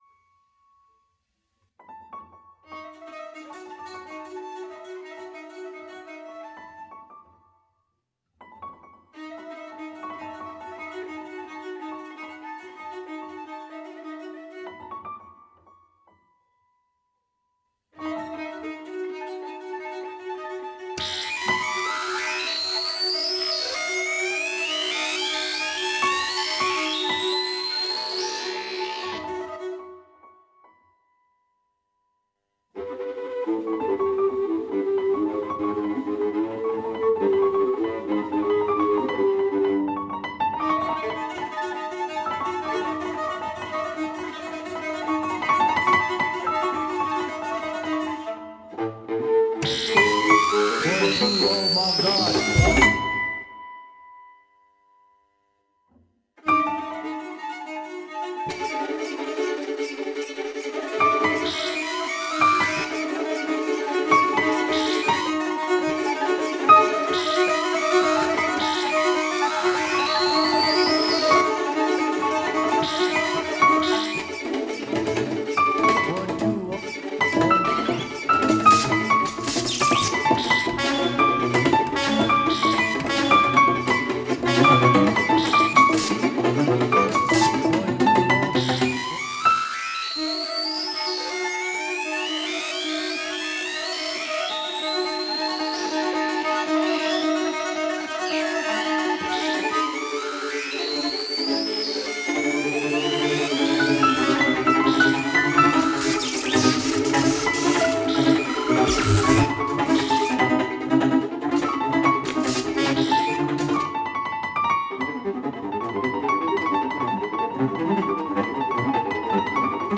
improvised music for violins, samples, and piano